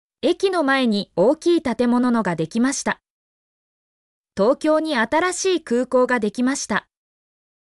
mp3-output-ttsfreedotcom-28_SDdM2OOx.mp3